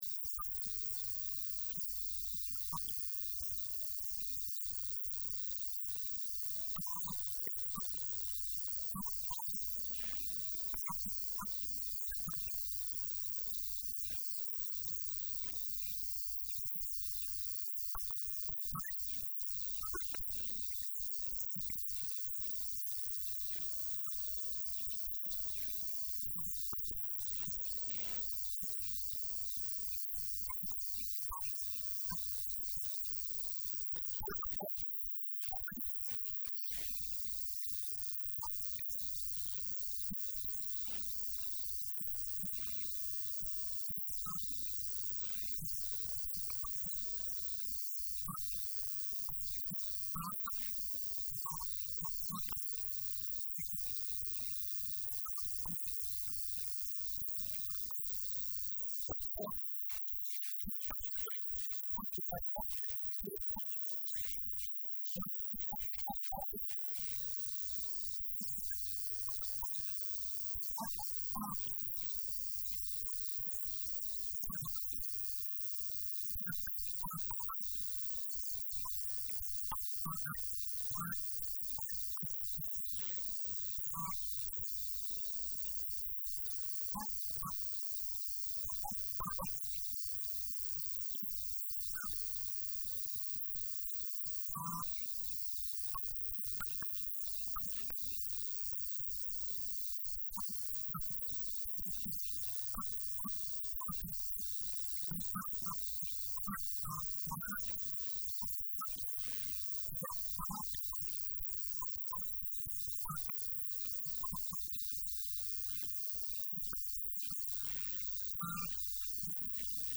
Guddoomiyaha Maamulka Gobolka Shabeelaha Hoose Ibraahin Aadan Najax oo la hadlay Radio Muqdisho Codka Jamhuuriyadda Soomaaliya ayaa tafaasiil dheeraad ah
Wareysi-Guddoomiyaha-Gobolka-Shabeelaha-Hoose-Ibraahim-Aadan-Najax.mp3